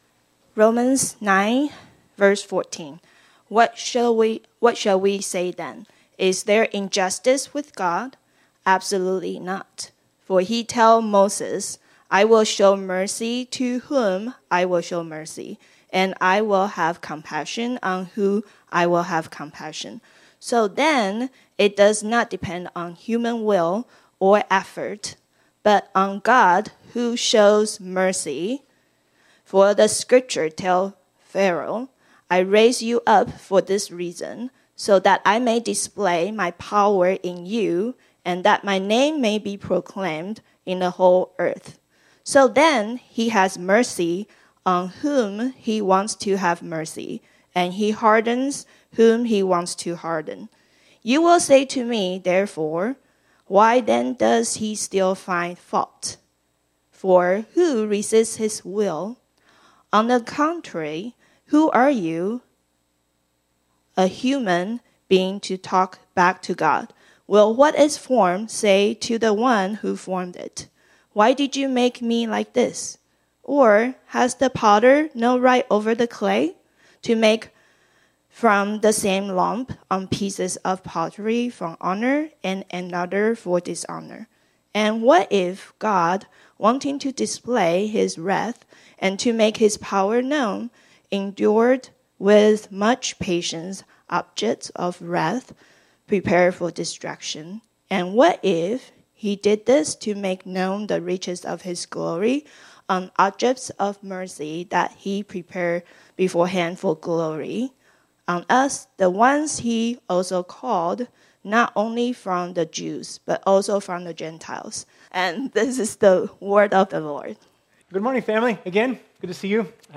This sermon was originally preached on Sunday, February 9, 2025.